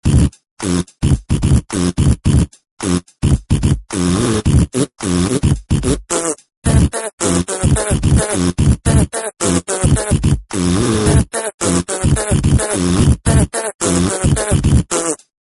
/64kbps) 16kbps (30.2кб) 32kbps (60.4кб) 48kbps (90.9кб) Описание: НепристойныЕ звуки ID 478323 Просмотрен 655 раз Скачан 36 раз Скопируй ссылку и скачай Fget-ом в течение 1-2 дней!